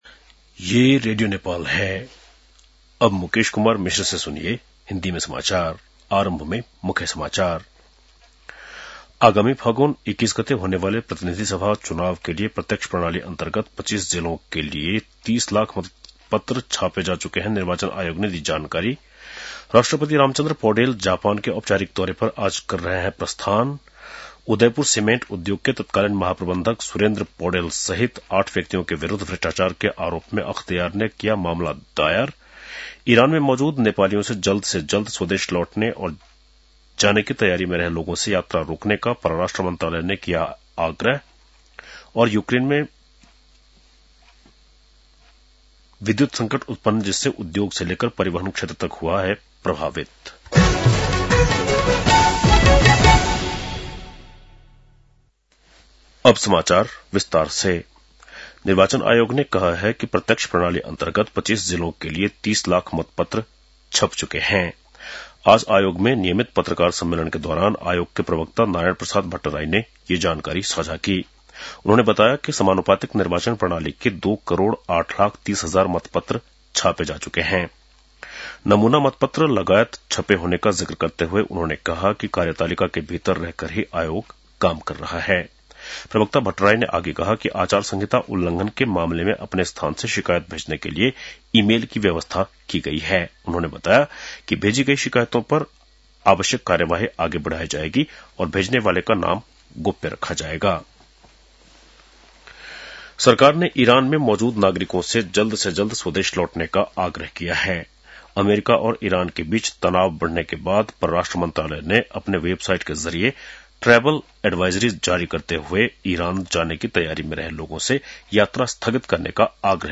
बेलुकी १० बजेको हिन्दी समाचार : १८ माघ , २०८२
10-pm-hindi-news-.mp3